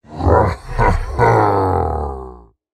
Minecraft Version Minecraft Version 1.21.5 Latest Release | Latest Snapshot 1.21.5 / assets / minecraft / sounds / mob / ravager / celebrate2.ogg Compare With Compare With Latest Release | Latest Snapshot
celebrate2.ogg